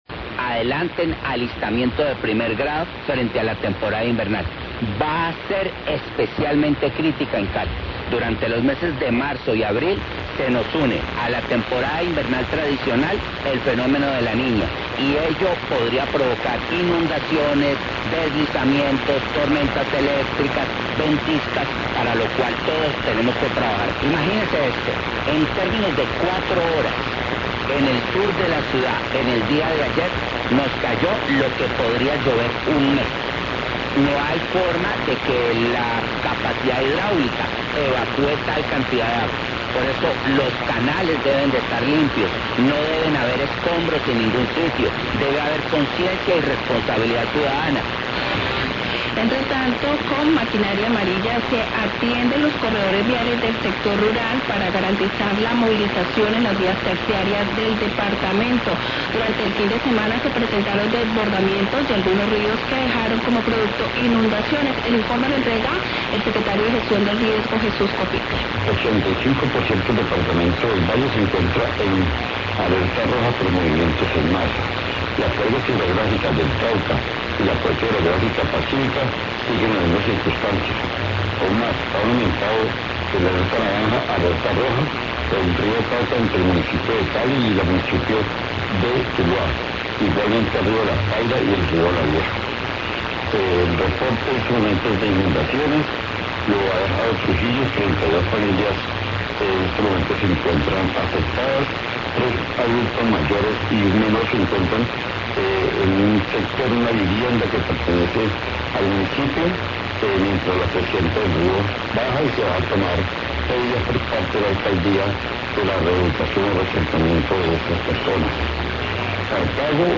Radio
Cali y el Valle del Cauca se encuentran en alerta por el incremento de lluvias en los últimos días como consecuencia del periodo de transición al fenómeno de la Niña. El Alcalde de Cali se refirió al riesgo que presenta para la ciudad la cantidad inusual de lluvias y, el secretario de Gestión de Riesgo del Valle habló sobre las crecientes y deslizamientos que se han presentado.